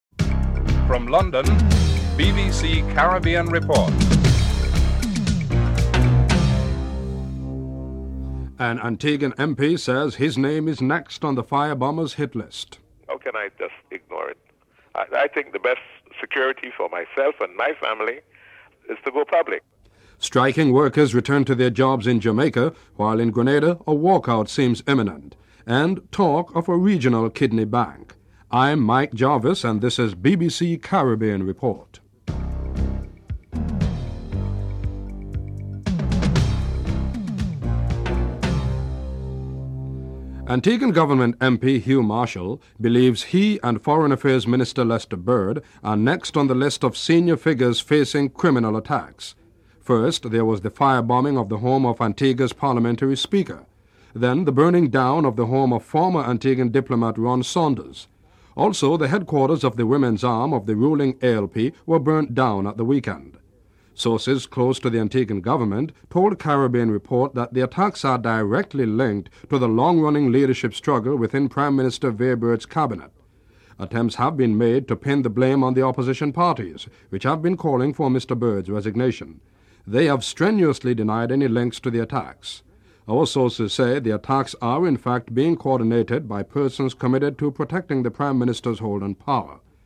Fluctuation in volume during interview in Segment 5.